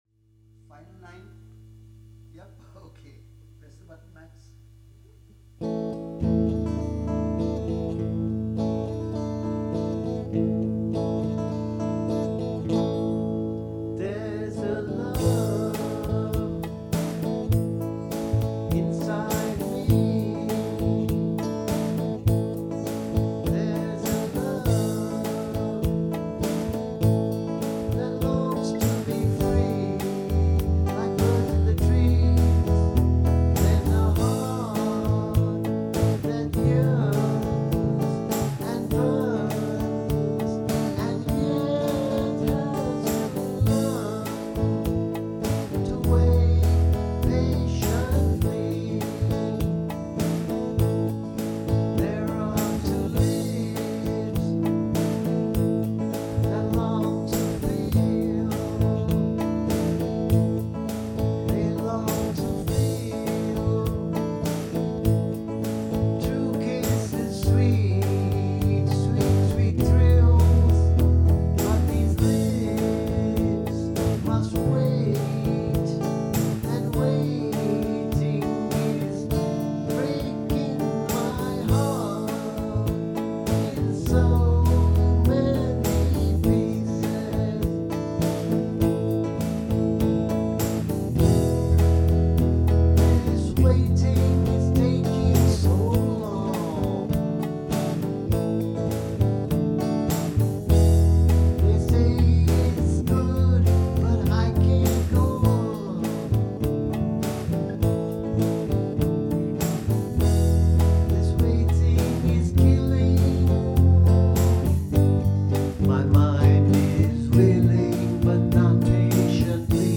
a ballad